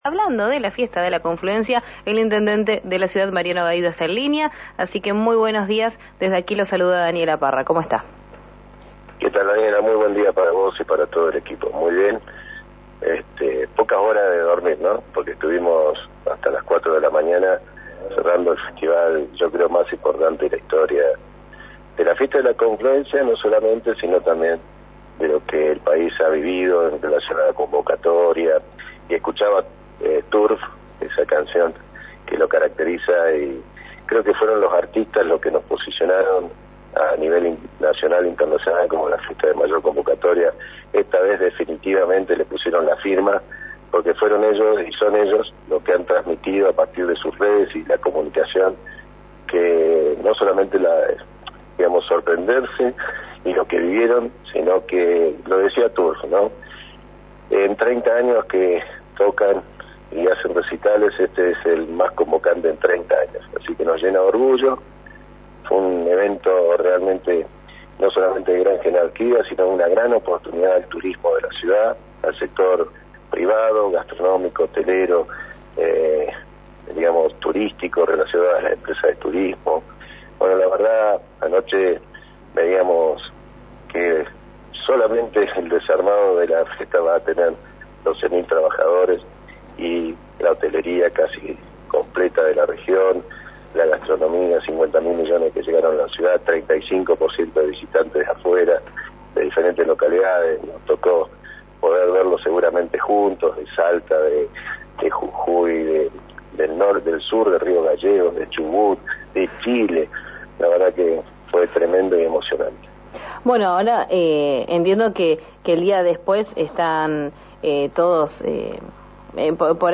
Este lunes, en diálogo con RÍO NEGRO RADIO, Gaido destacó algunas cifras: